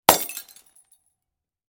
Звук упавшего сердца на землю